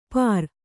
♪ pār